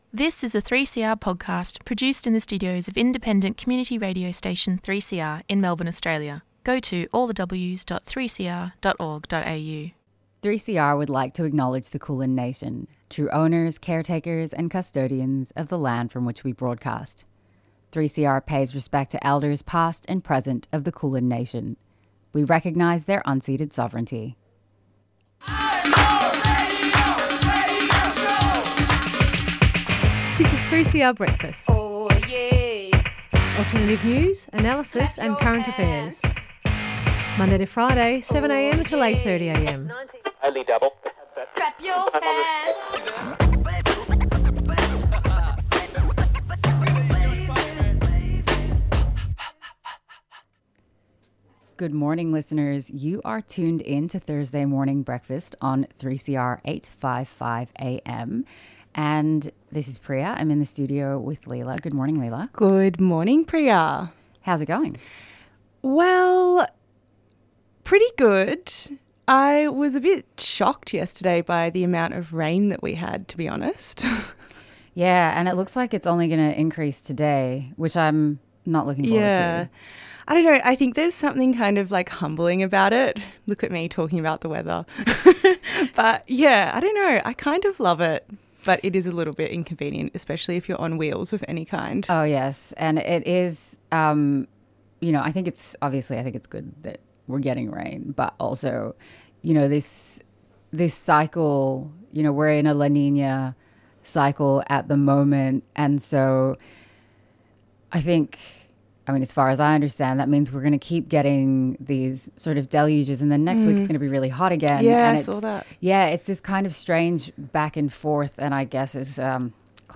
We listened to speeches from the Australian Education Union teachers strike outside Victorian Parliament on Tuesday the 24th of March